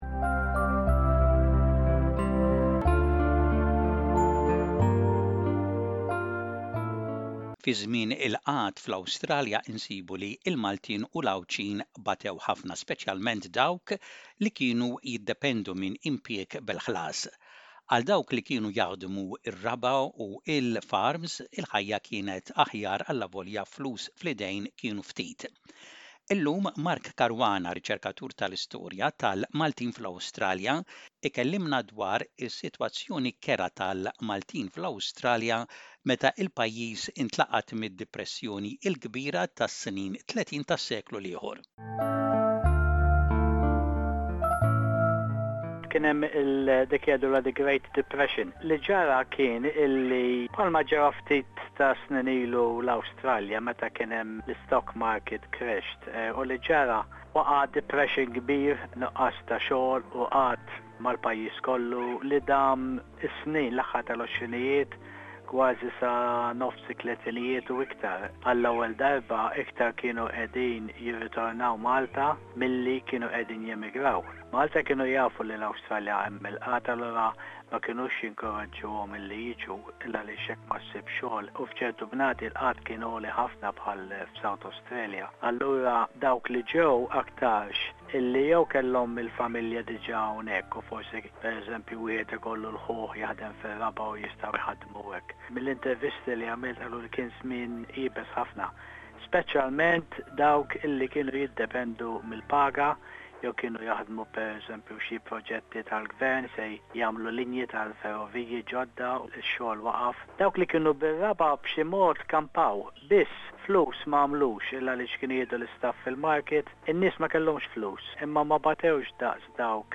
F’dan iż-żmien ħafna Maltin li kienu l-Awstralja kellhom jerġgħu lura Malta u dawk li baqgħu l-Awstralja għaddew minn tbatijiet u diffikultajiet kbar bla xogħol. L-istoriku u r-riċerkatur tal-istorja tal-Maltin fl-Awstralja, jitkellem dwar id-diffikultajiet kbar li ltaqgħu magħhom il-Maltin f’dak iż-żmien.